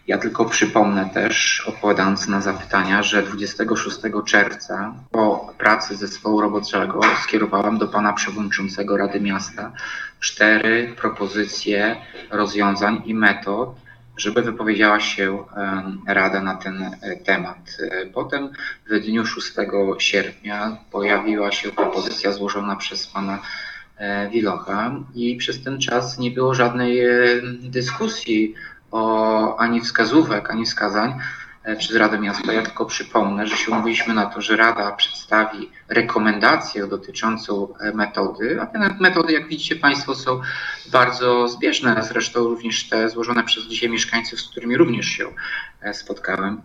Do zarzutów radnych odniósł się prezydent Ełku Tomasz Andrukiewicz.